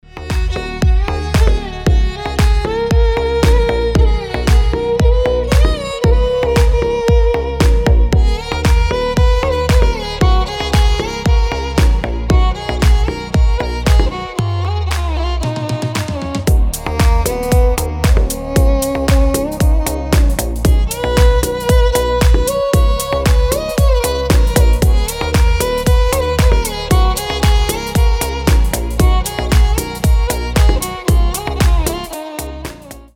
• Качество: 320, Stereo
deep house
восточные мотивы
без слов
красивая мелодия
скрипка
Красивый восточный дипчик.